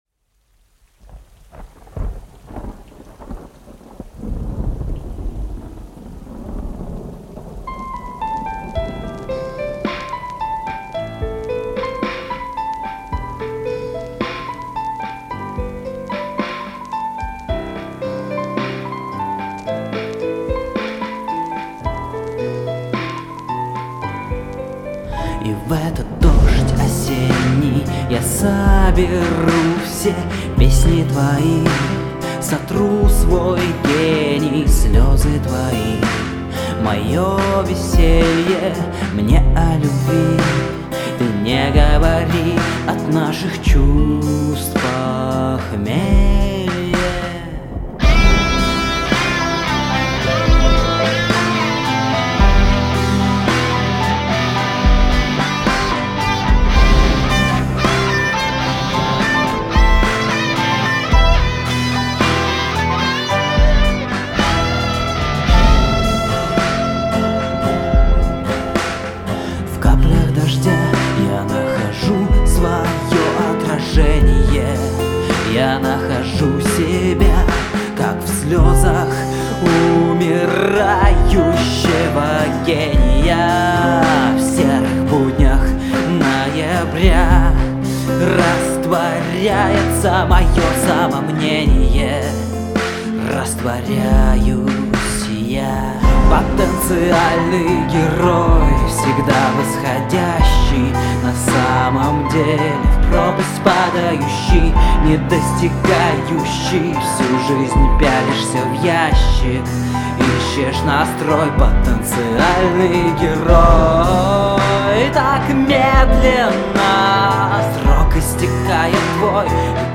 Критика вокала и аранжировки, Rock, авторская музыка
Это моя первая работа с сольным вокалом, песня собственного сочинения, как вы уже поняли. Есть деффект, над которым работаю - картавость.
Финальное соло еще скорее всего перепишу, не совсем чисто сыграно и ноты не цепляют.